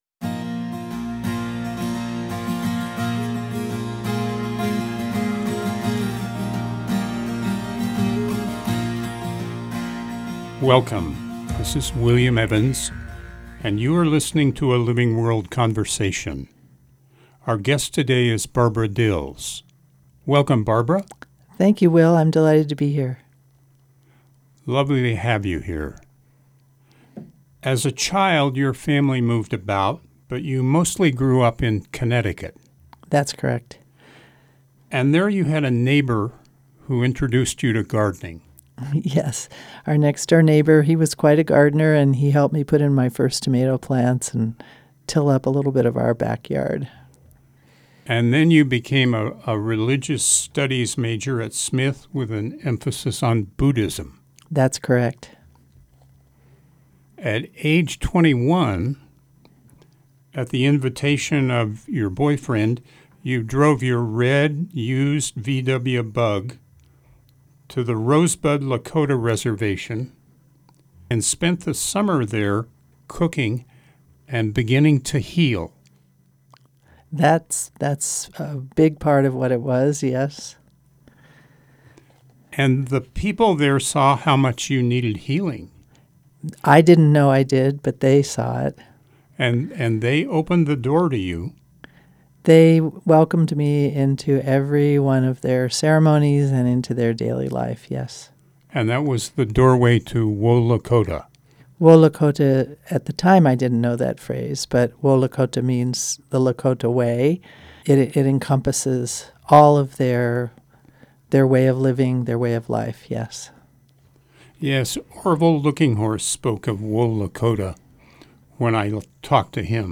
Shifting Gears features conversations with people making life-sustaining choices.